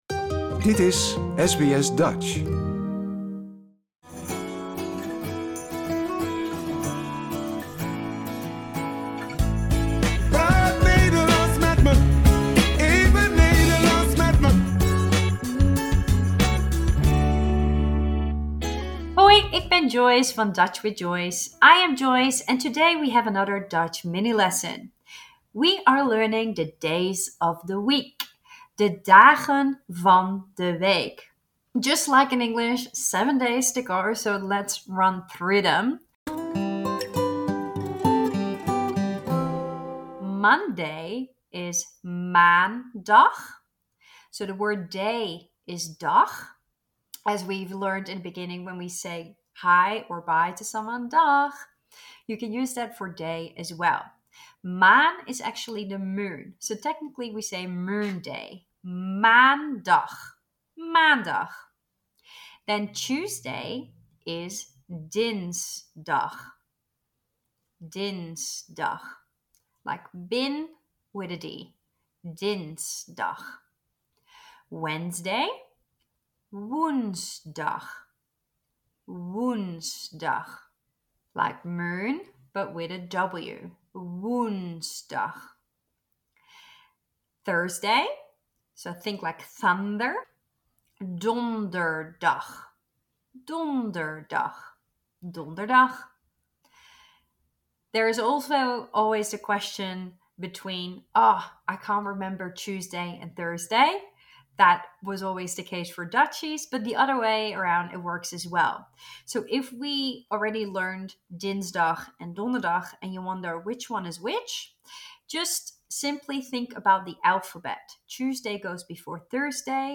In this podcast series you will learn a few Dutch words and pronunciations every week in a few minutes.